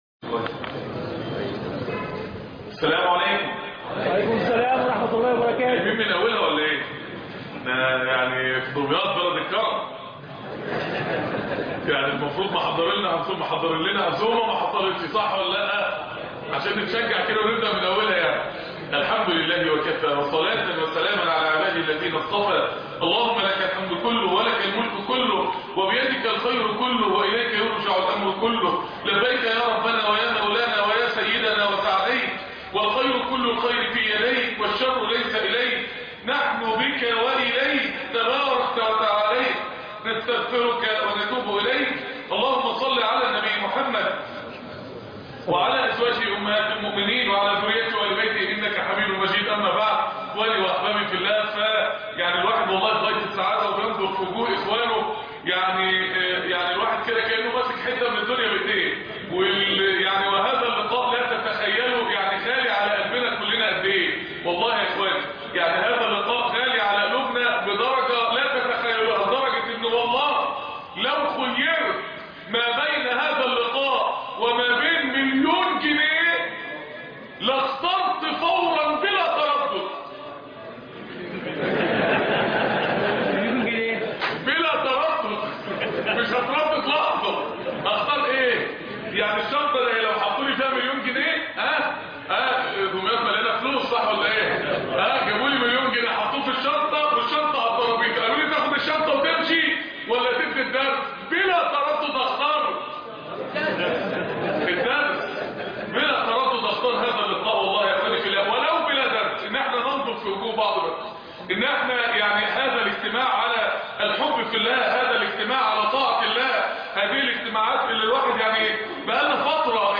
ندوة جدد إيمانك .